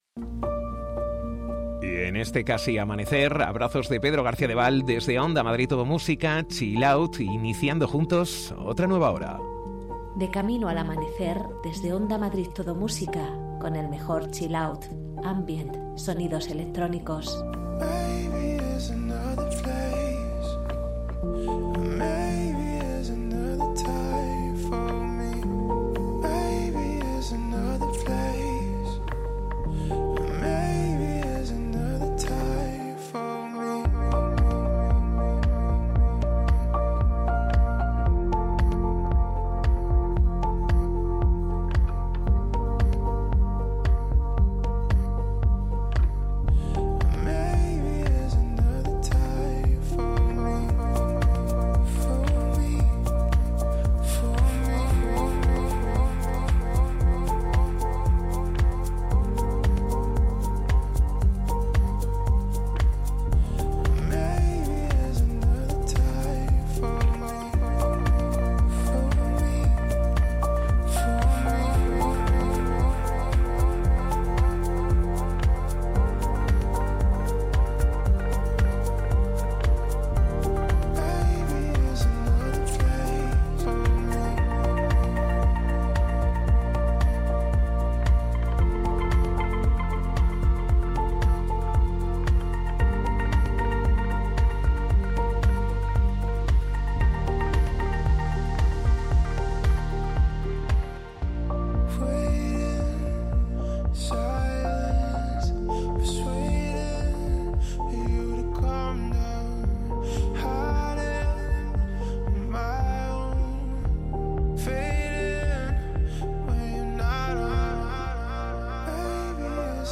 Viaja con nuestros lentos a diferentes momentos de tu vida en las madrugadas de Onda Madrid Todo Música.